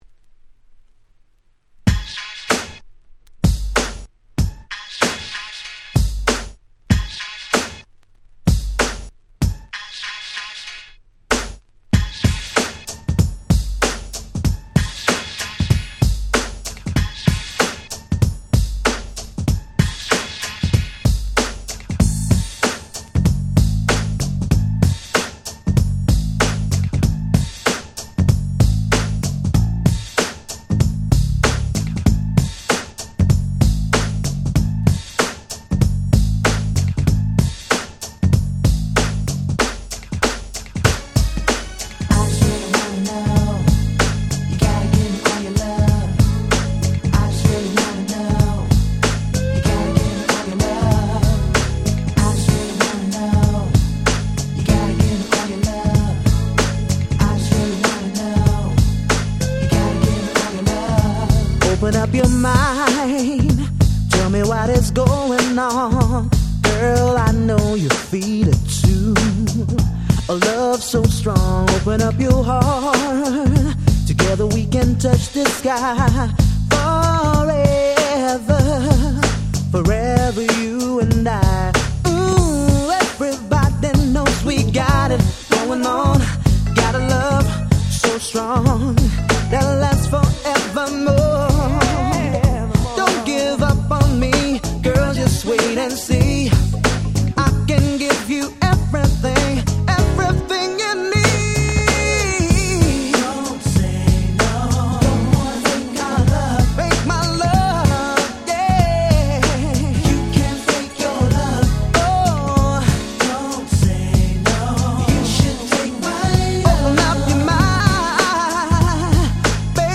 95' Nice EU R&B !!
またこのアルバムの内容が非常に最高でこの曲を含む6曲がシングルカット、しかもその全てが爽快なダンスナンバー！！
UK Soul